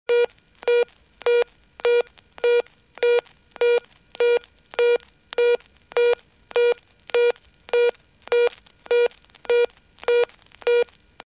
Звуки гудков телефона
Короткие гудки и брошенный звонок